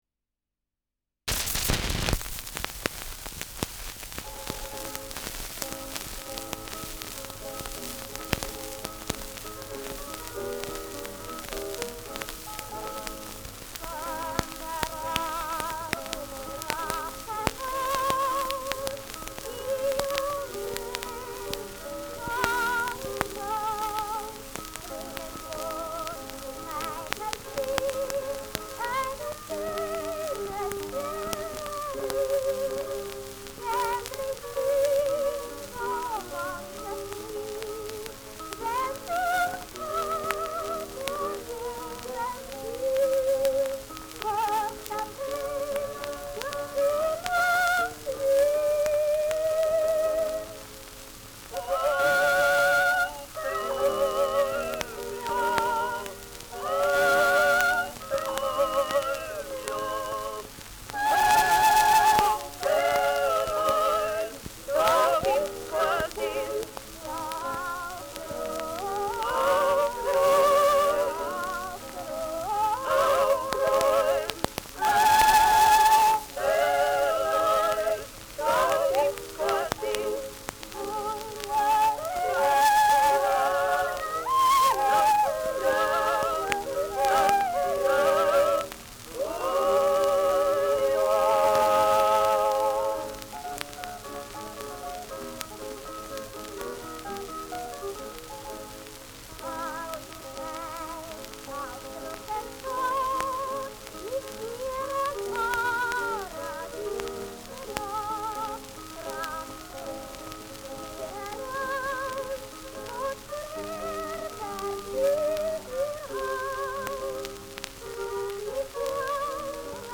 Schellackplatte